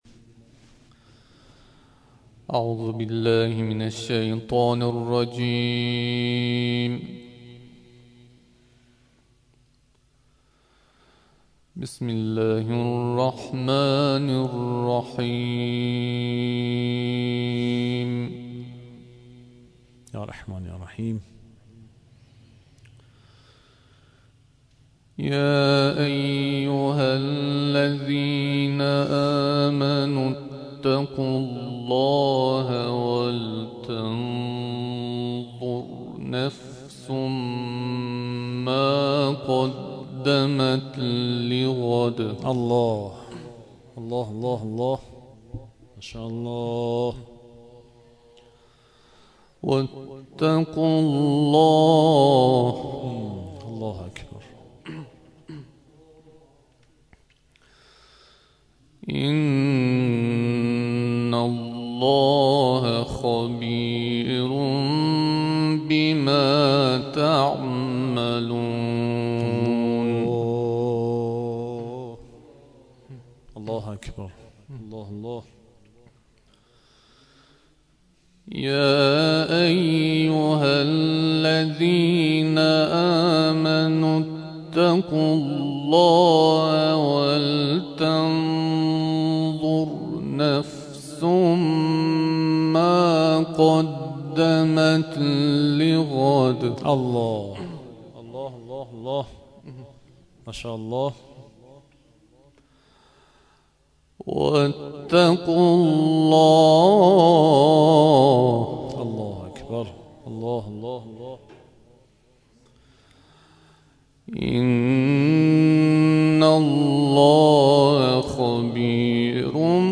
صوت | تلاوت